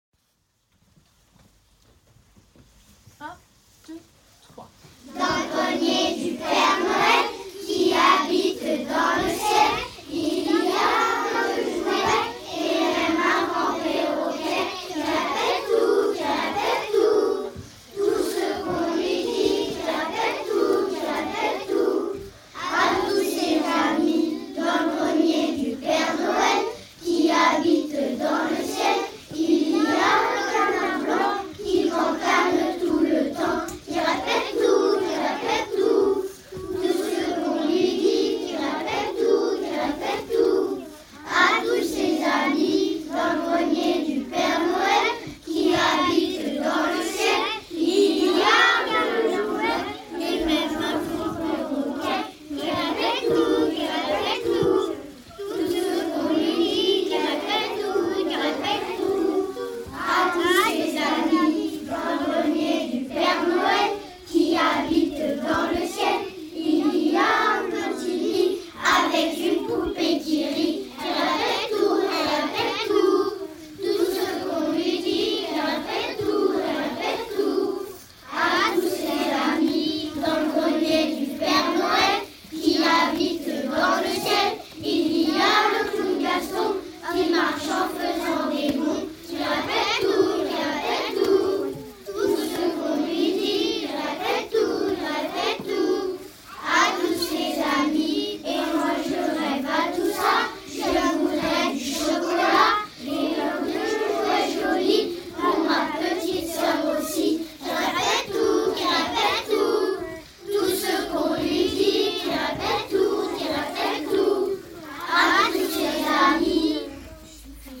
Chant de Noël des CP/CE1/CE2
Voici une version « studio » du chant de Noël interprété par les CP/CE1/CE2 pour les parents n’ayant pas pu être présents vendredi soir.
Chant-Noel-CP-CE1-CE2.mp3